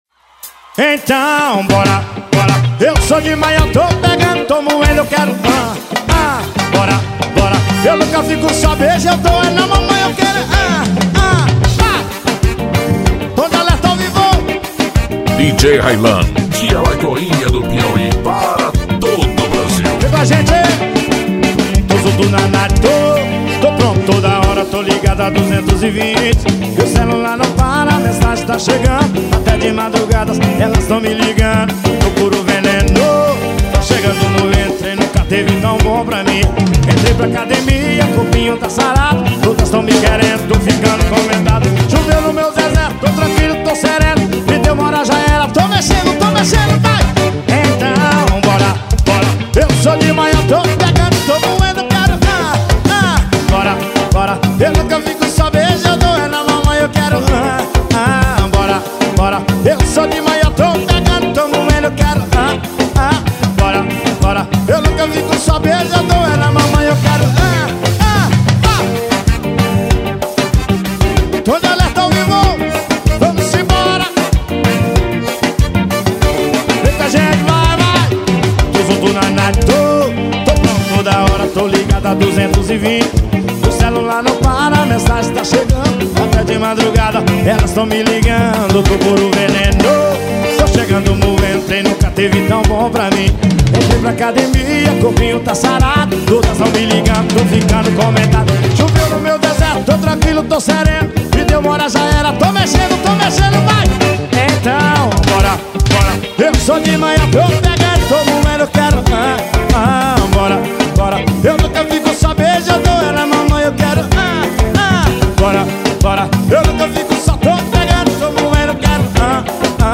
passagens de som para testar paredão.